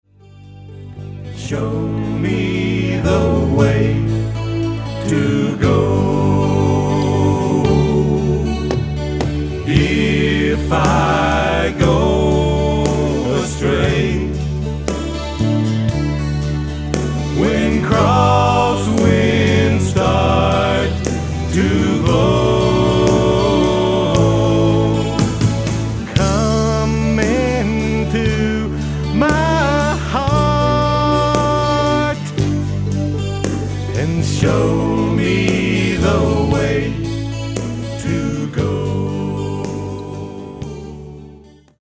Autoharp, Lead & Harmony Vocals
Guitar